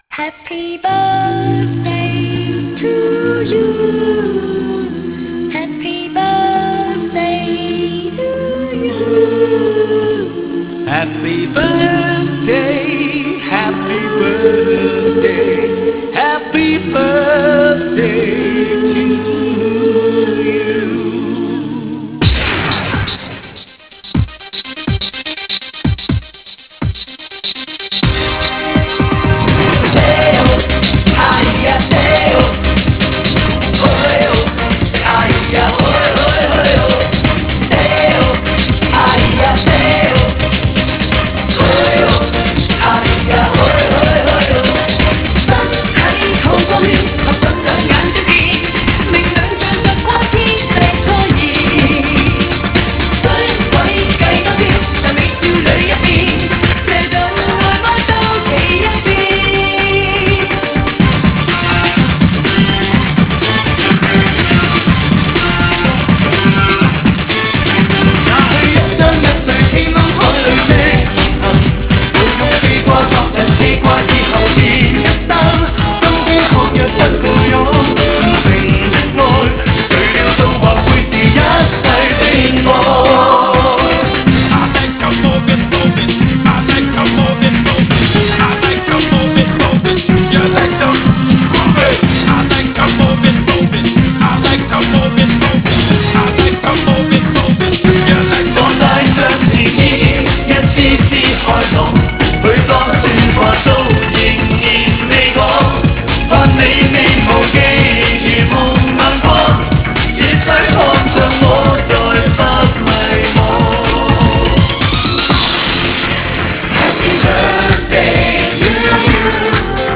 This is a mix of several Cantonese songs